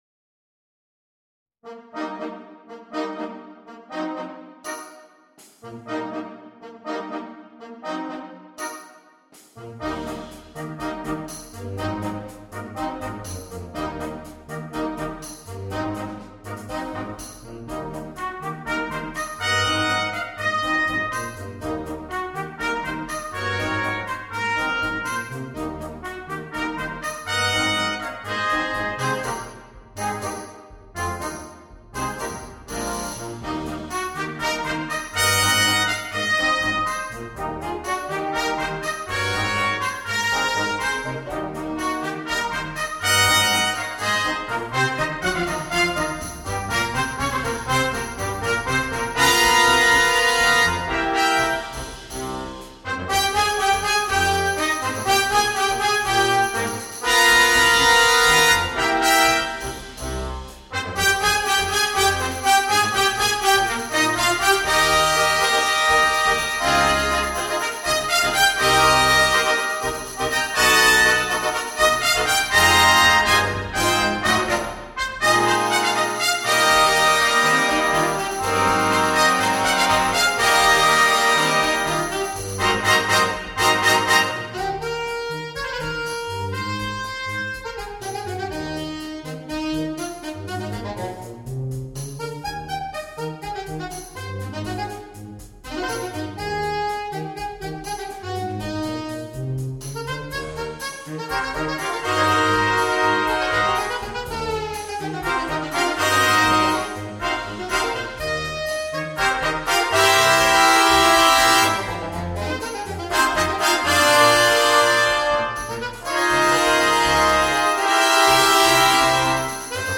на биг-бэнд